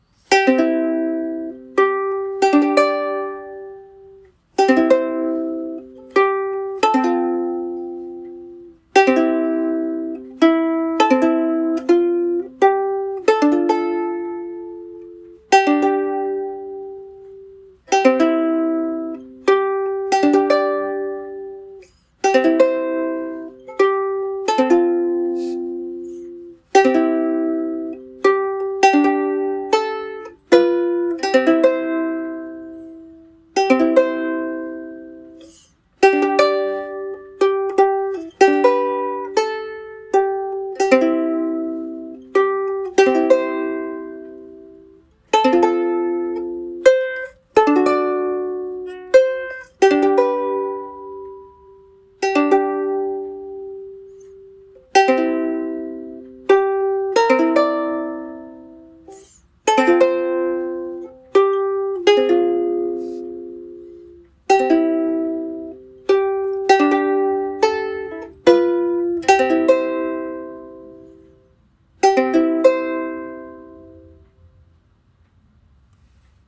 デモ演奏☟
これは桂の木によるソプラノサイズのウスレレです。
・この弱いテンションでも心地よい音色を実現するために、リュートやバロックギターなど古楽器用の弦を海外から取り寄せて選びました。
・音量が大きな方とは言えませんが、癒しの音としては十分な音量が出ます。
弦　Aquila New nyle gut